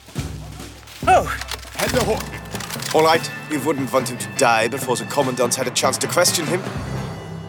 German Adult